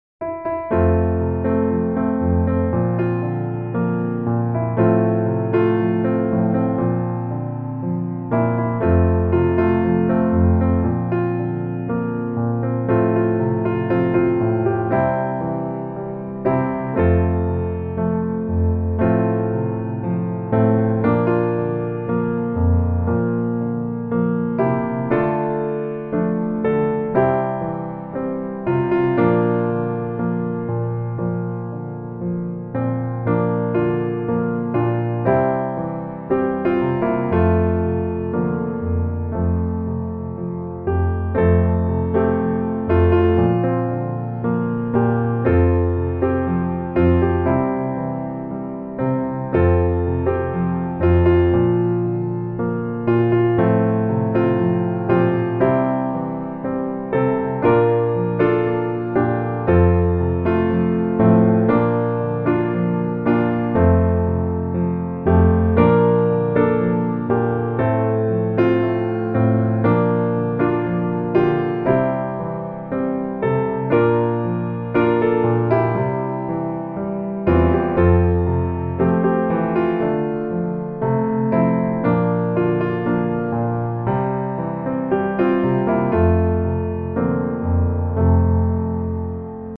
F大調